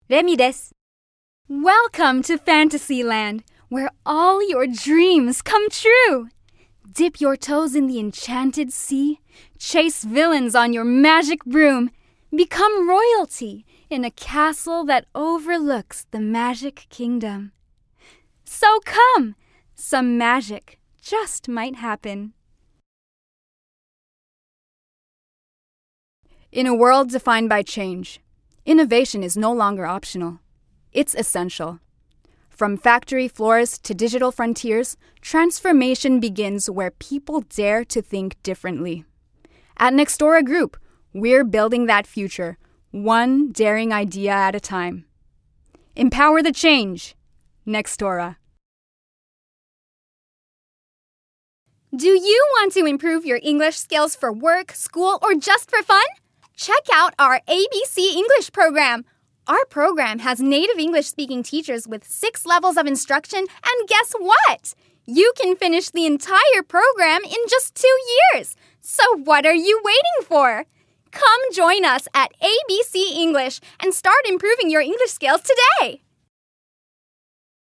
◆ナレーション